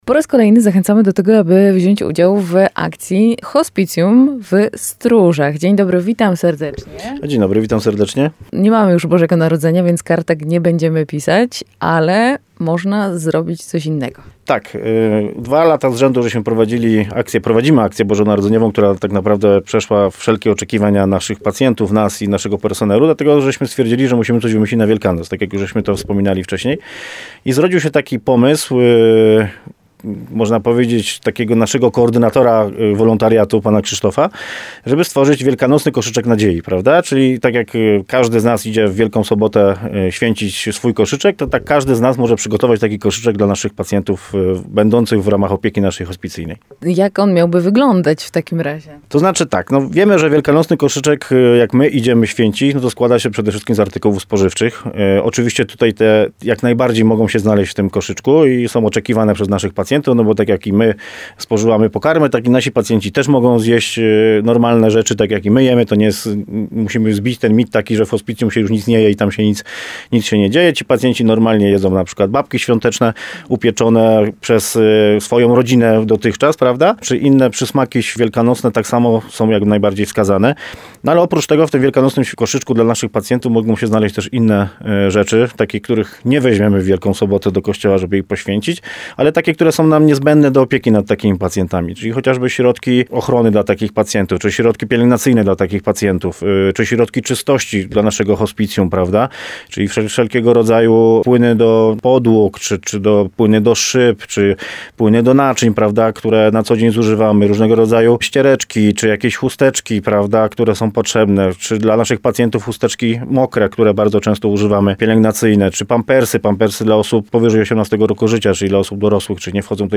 24rozmowa_hospicjum.mp3